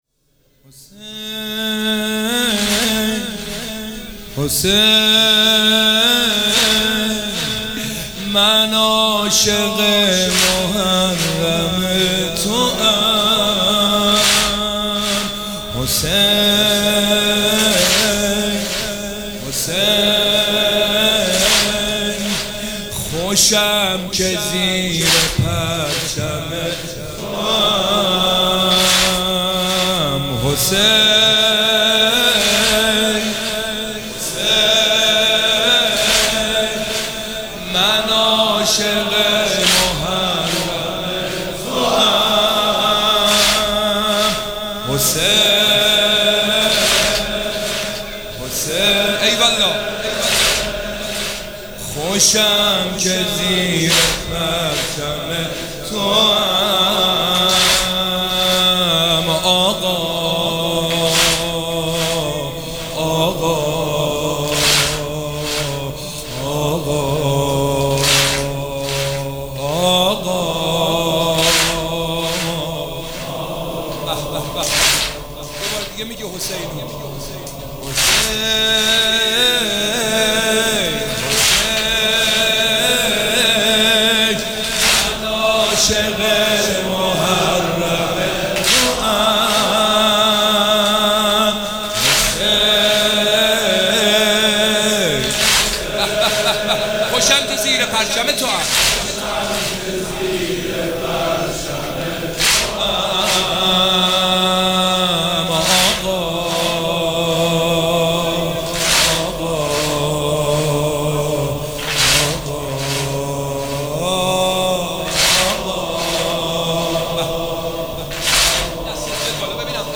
محفل عزاداری شب سوم محرم
در مجموعه‌ فرهنگی‌ سرچشمه برگزار شد.